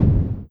EXPLOSION_Subtle_Muffled_Dark_stereo.wav